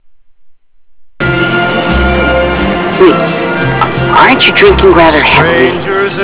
Click to hear Corey Haim